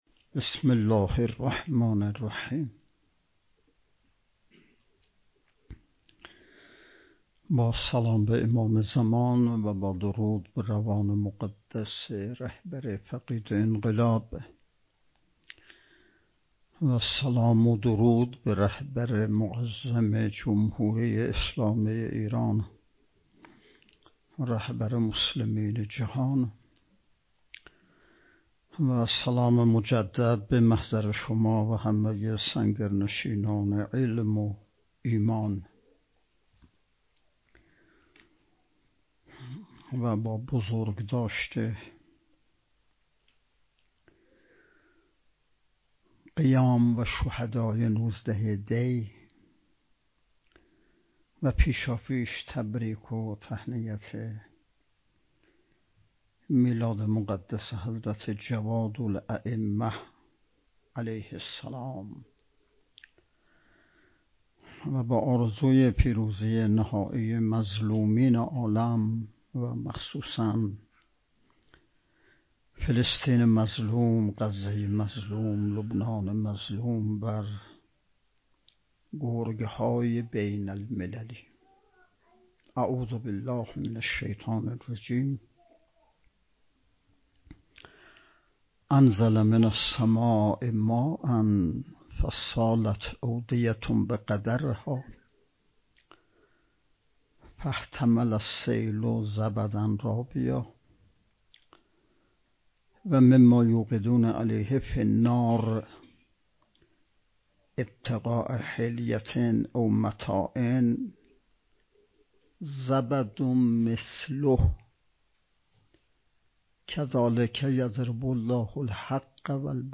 سیزدهمین نشست ارکان شبکه تربیتی صالحین بسیج با موضوع تربیت جوان مؤمن انقلابی پای کار، صبح امروز ( ۲۰ دی) با حضور و سخنرانی نماینده ولی فقیه در استان، برگزار شد.